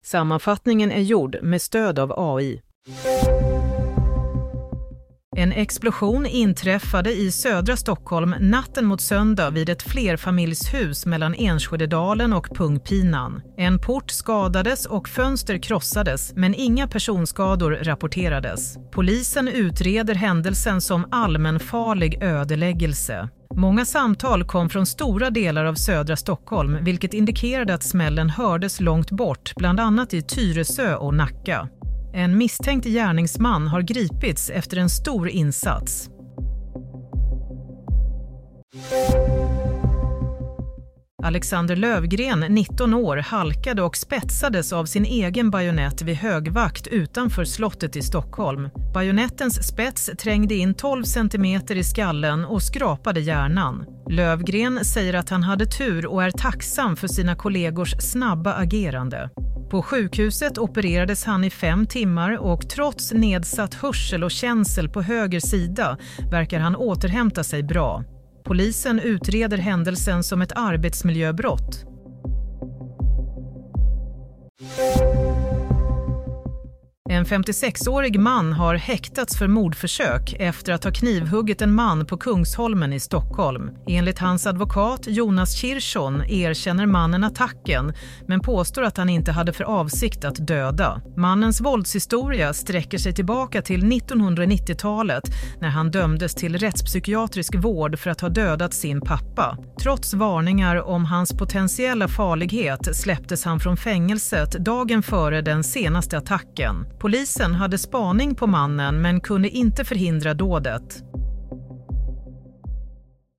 Sammanfattningen av följande nyheter är gjord med stöd av AI.